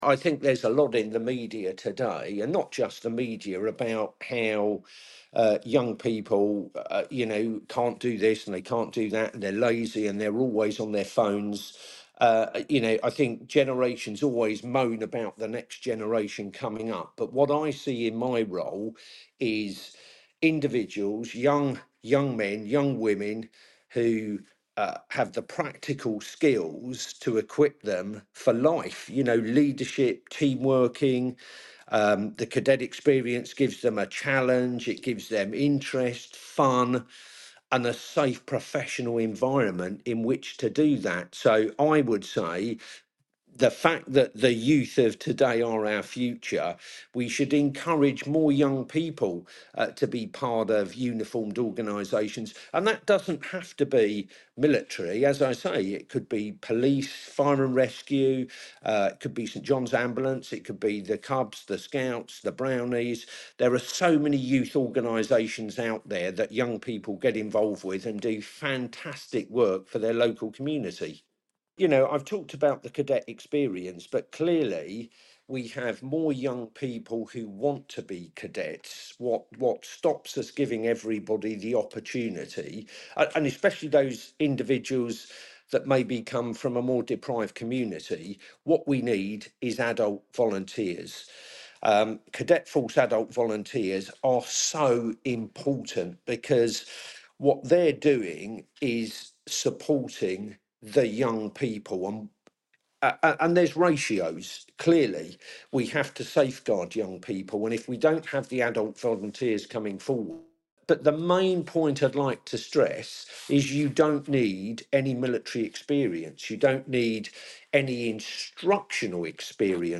LISTEN: Deputy Lieutenant of Kent Brigadier Andrew Wood says Cadets are an important part of society - 11/11/2024